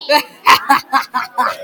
Ccreepy Laugh Two